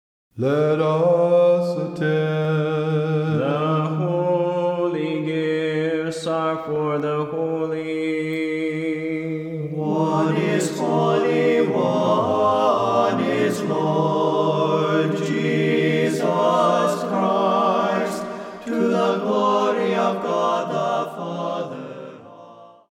Serbian Melody